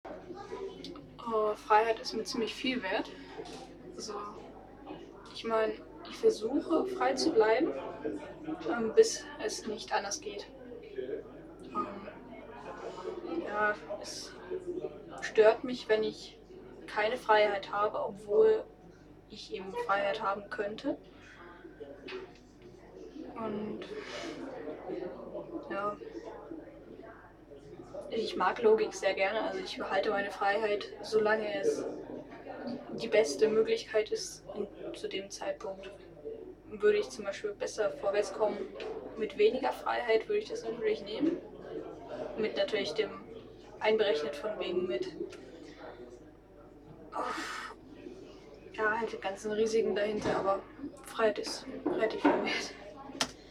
Standort der Erzählbox:
MS Wissenschaft @ Diverse Häfen